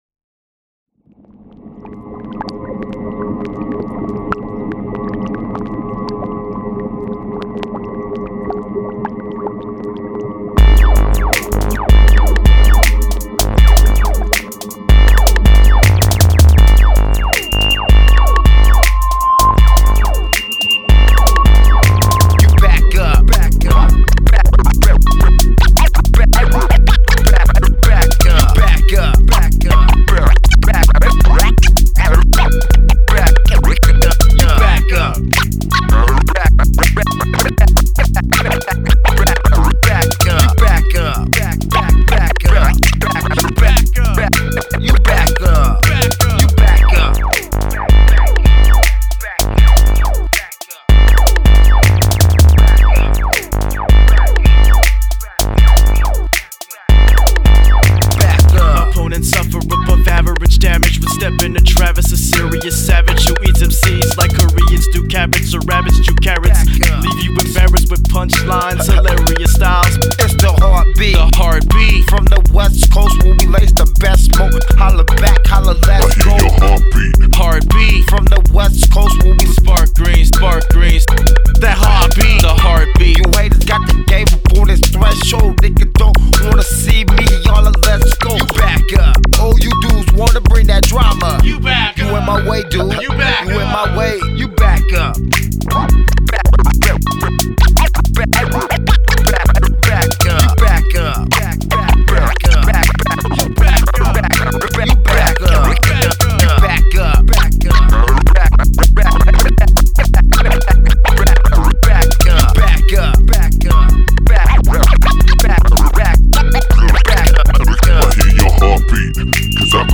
Recorded at Ground Zero Studios and Seattle Chop Shop